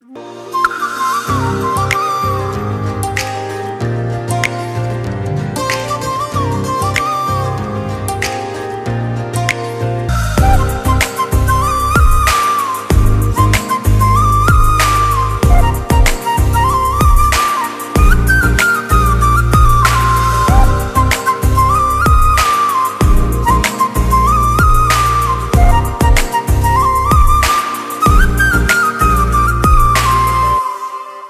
• Romantic and emotional background music
• Clean instrumental without lyrics